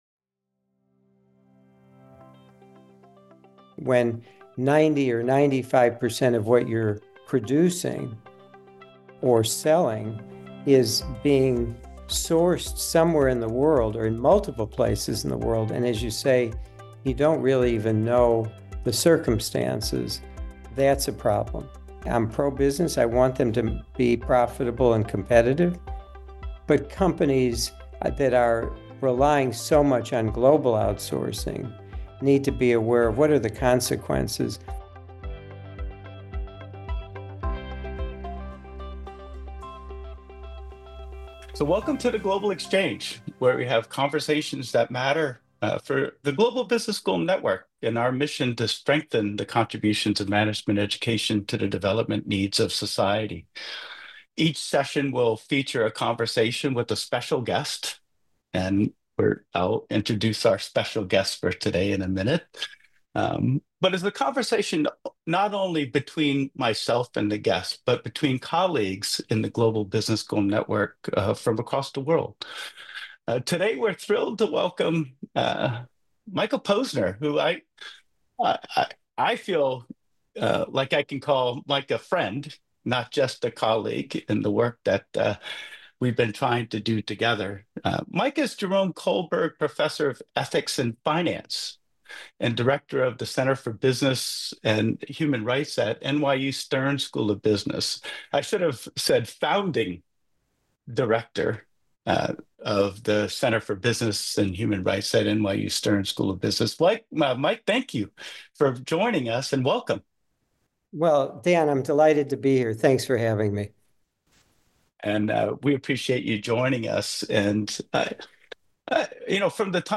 The Global Exchange: A Conversation with Mike Posner